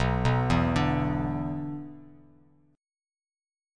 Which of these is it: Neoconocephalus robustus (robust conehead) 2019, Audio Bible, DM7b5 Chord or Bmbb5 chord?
Bmbb5 chord